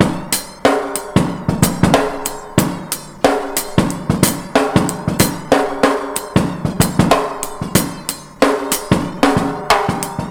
Index of /90_sSampleCDs/Best Service ProSamples vol.24 - Breakbeat [AKAI] 1CD/Partition B/ONE HAND 093